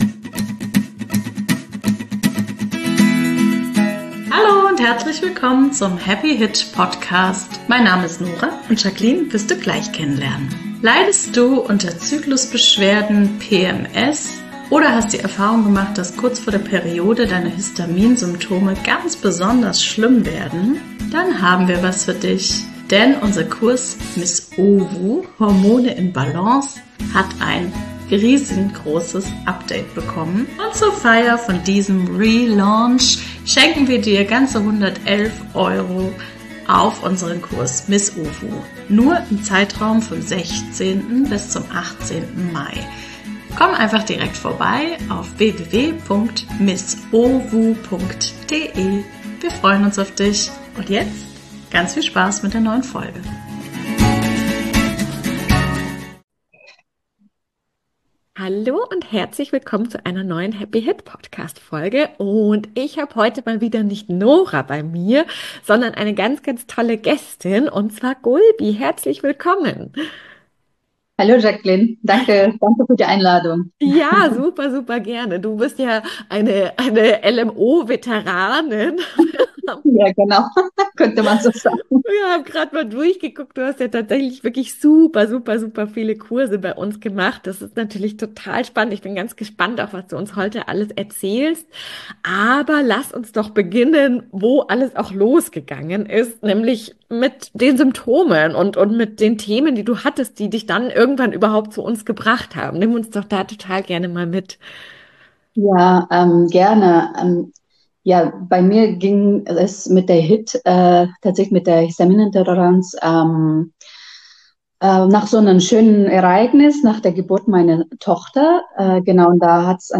#123 [Interview] Wie Extreme Histaminintoleranz Symptome Weggegangen Sind